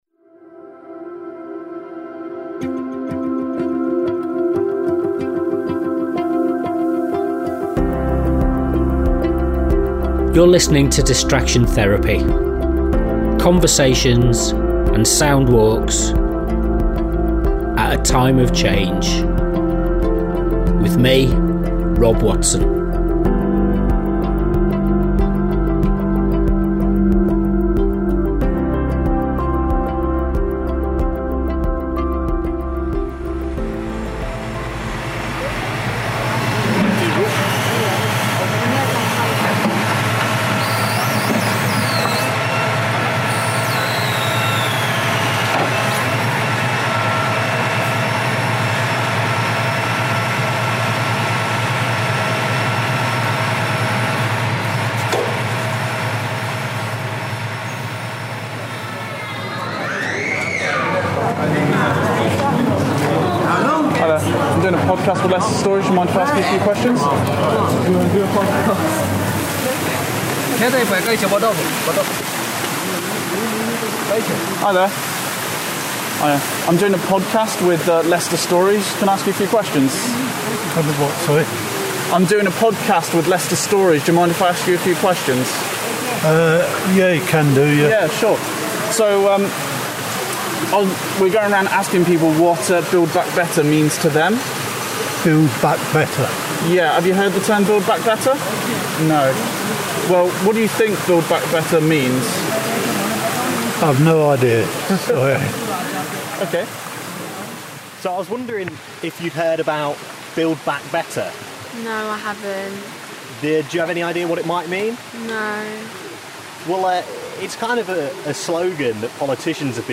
Leicester Smart City Vox-Pops Wednesday 14th September